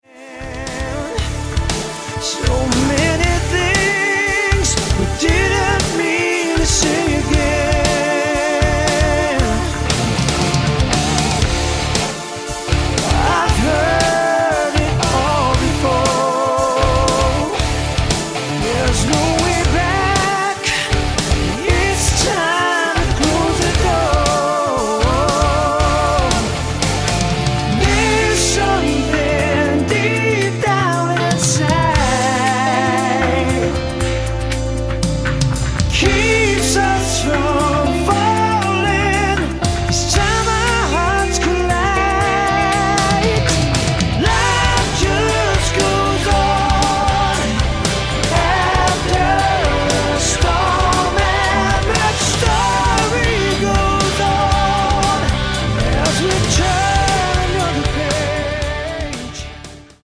Lead Vocals and backing Vocals
Lead Guitars & Rhythm Guitars
Bass
Drums
Keyboards and piano